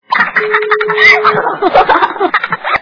Детский смех - Cocuk gulme Звук Звуки Дитячий сміх - Cocuk gulme
При прослушивании Детский смех - Cocuk gulme качество понижено и присутствуют гудки.